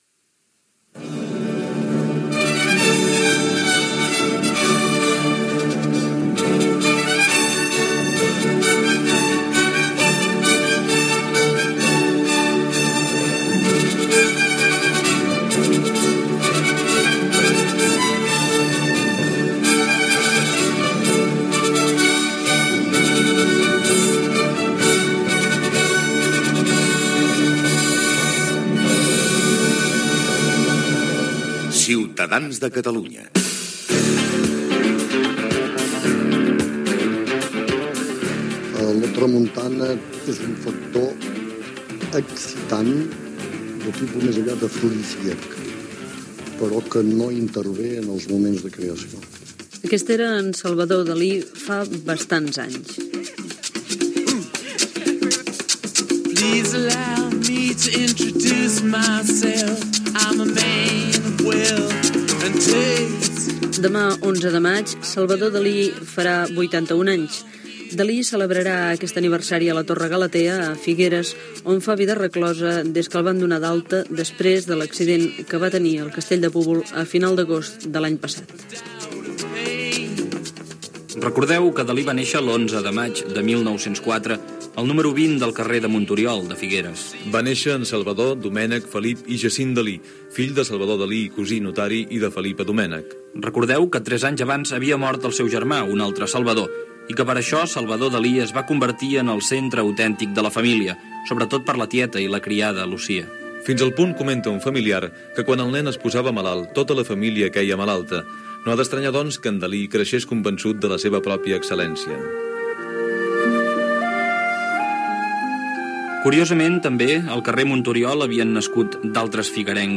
Entrevista al periodista Màrius Carol que ha participat en l'escriptura d'un llibre sobre Dalí, indicatiu